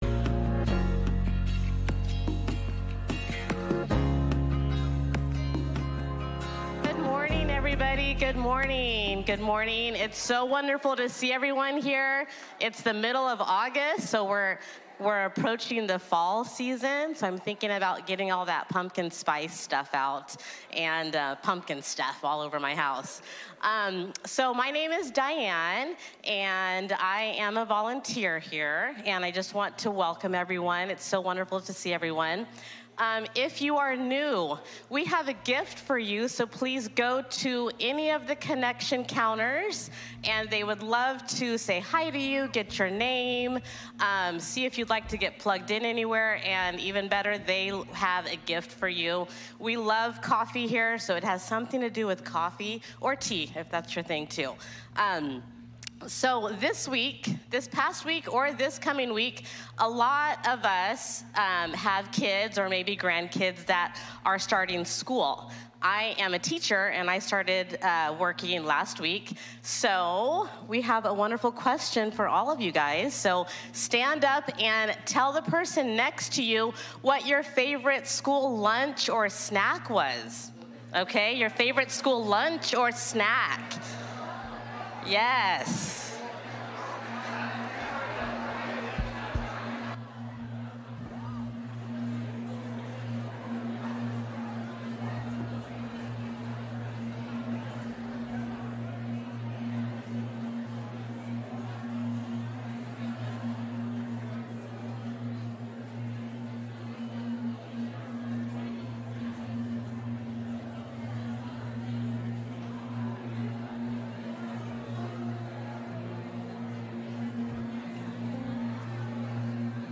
A message from the series "Believe and Have Life."